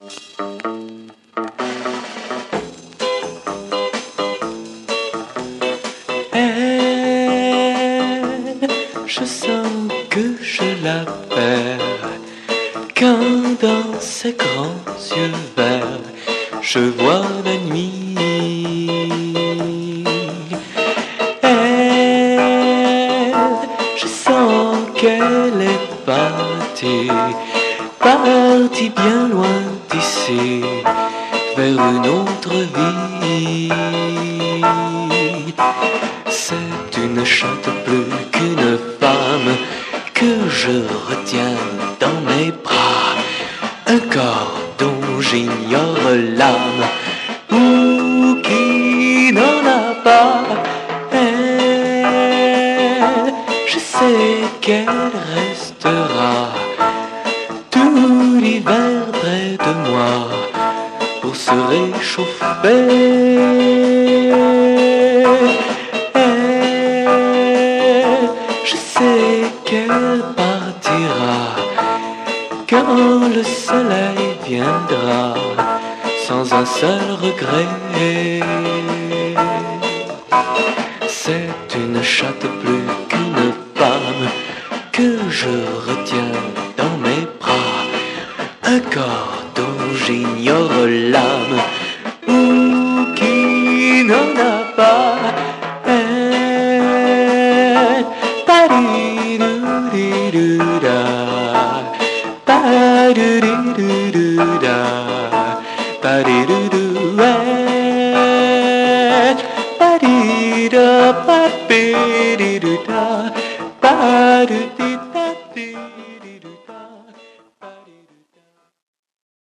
Moody Dutch/French Freakbeat Mod